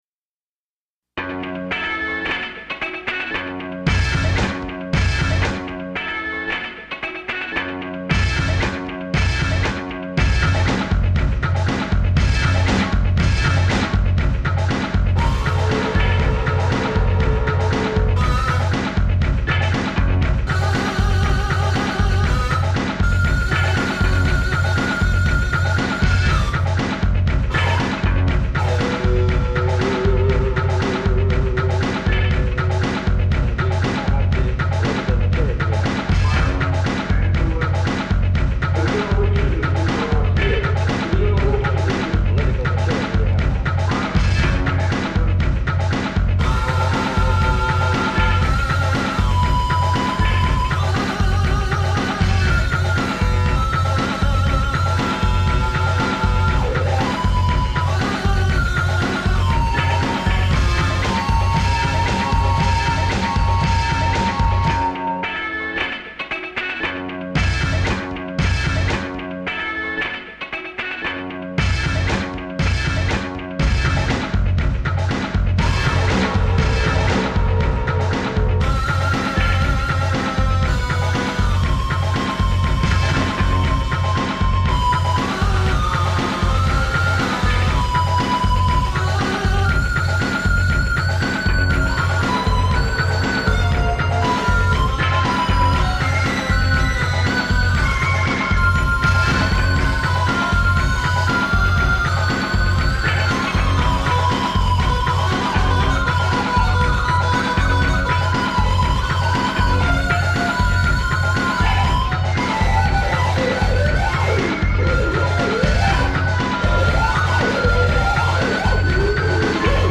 upbeat electronic pieces
sampling keyboards and synthesizer